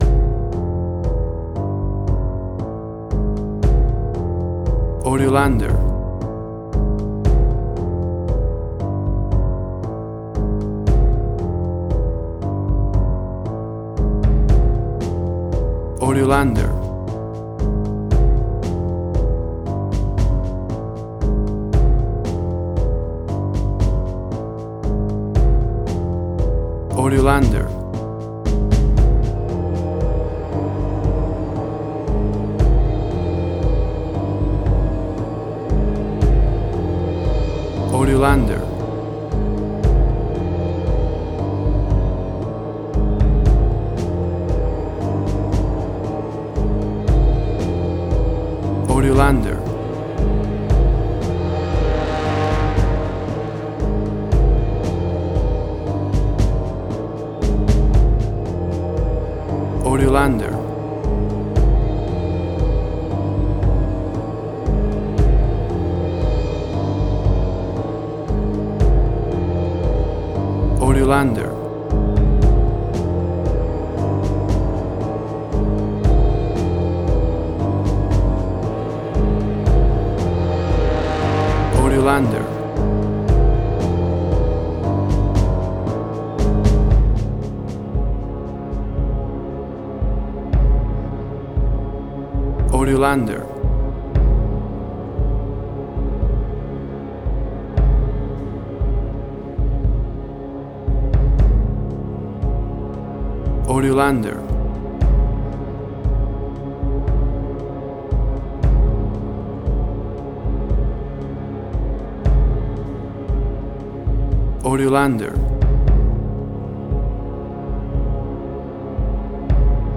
Suspense, Drama, Quirky, Emotional.
Tempo (BPM): 116